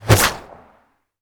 bullet_leave_barrel_02.wav